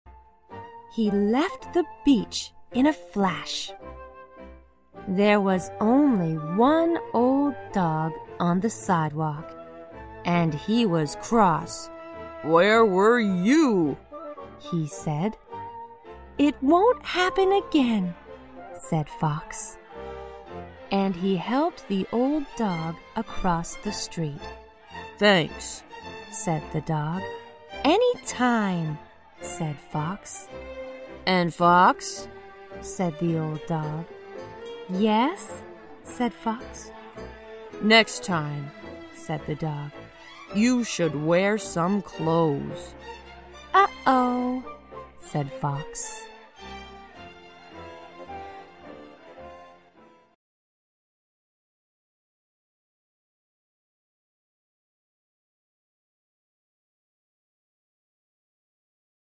在线英语听力室小狐外传 第14期:海滩的听力文件下载,《小狐外传》是双语有声读物下面的子栏目，非常适合英语学习爱好者进行细心品读。故事内容讲述了一个小男生在学校、家庭里的各种角色转换以及生活中的趣事。